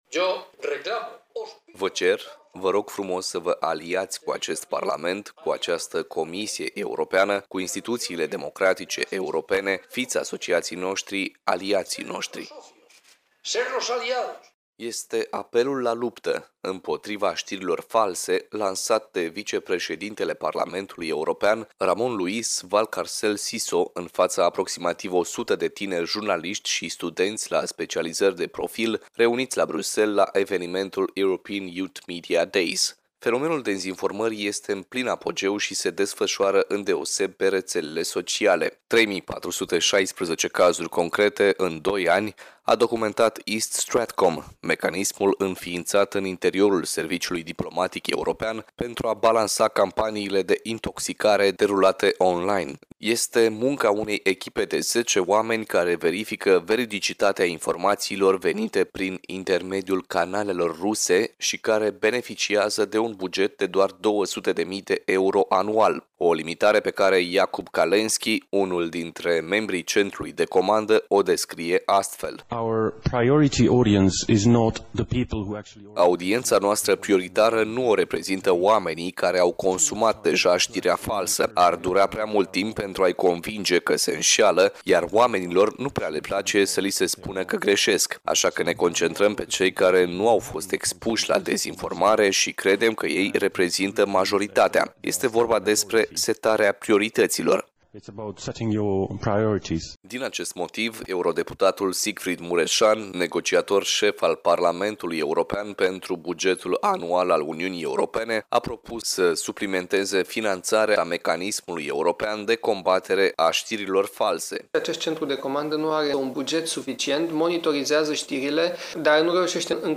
Colegul nostru a realizat un radioreportaj pe acest subiect: